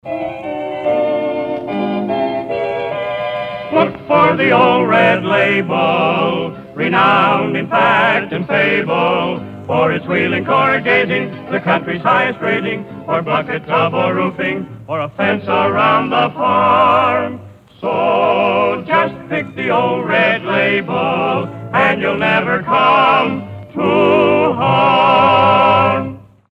Sample sound files from the radio program:
"Look for Red Label" advertisements, 16 and 27 seconds respectively.